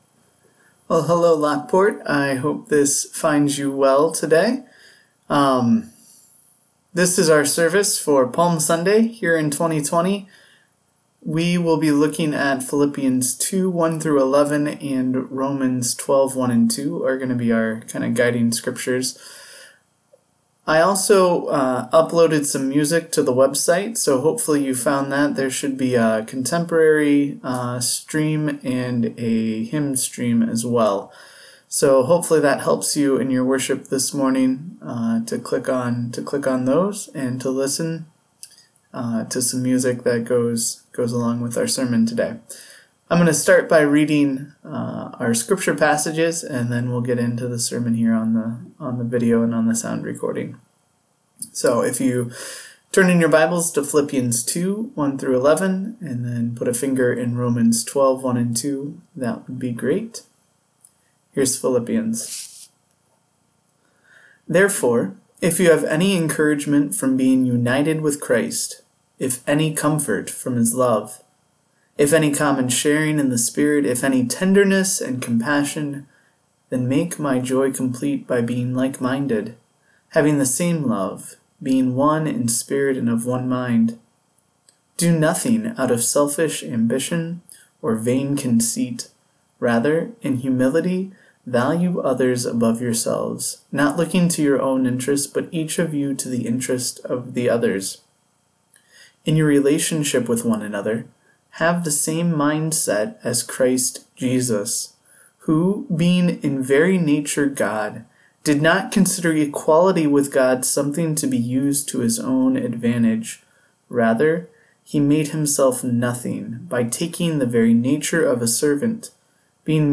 A Prophet’s Call to Repentance – Sermon
Palm Sunday sermon about the humble king Jesus. Scripture from Philippians 2.1-11 and Romans 12.1-2.